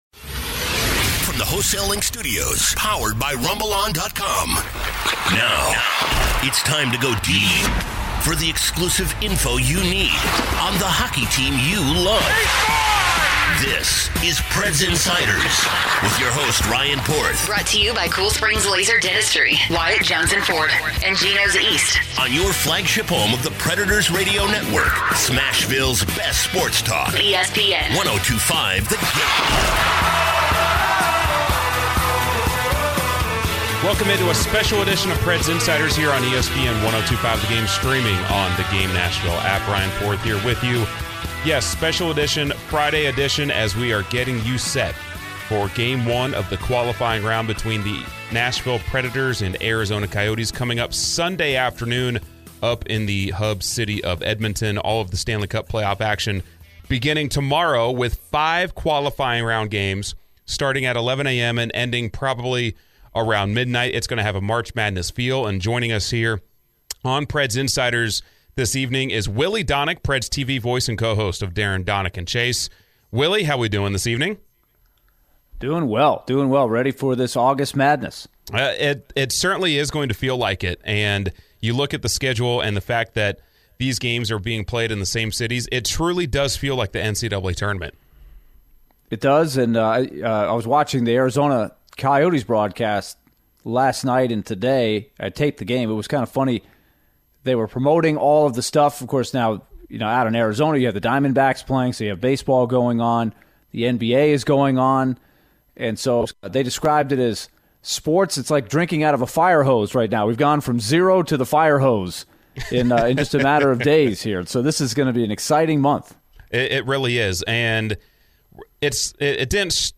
-24:40, Jeff Marek from Sportsnet phones in to discuss what he's enjoyed about the exhibition games, what the Preds must do to make a run, and his excitement for a full month of all day hockey starting tomorrow.